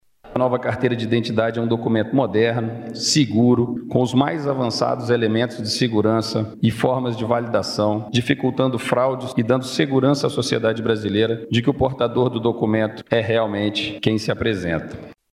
O novo documento é considerado mais seguro porque permitirá a validação eletrônica de sua autenticidade por QR Code, inclusive off-line. Como destaca o ministro da Justiça e Segurança Pública, Anderson Torres.
Sonora-Anderson-Torres-ministro-da-Justica-e-Seguranca-Publica.mp3